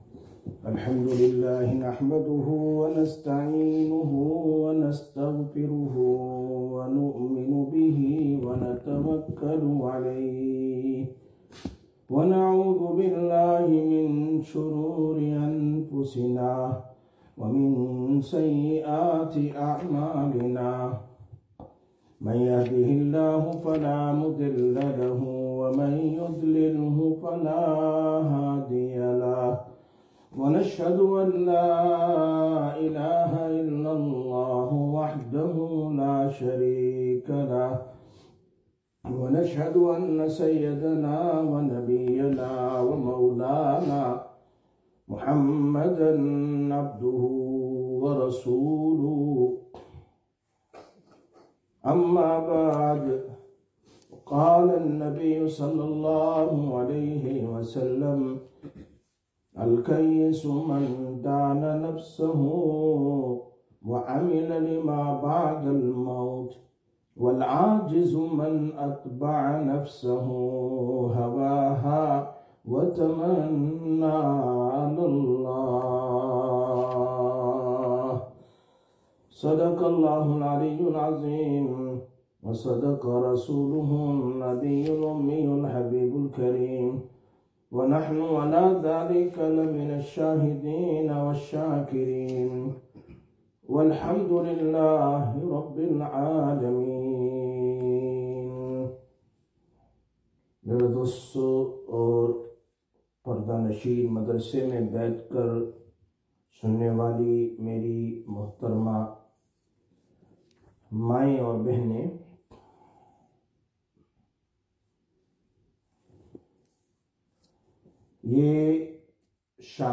05/02/2025 Sisters Bayan, Masjid Quba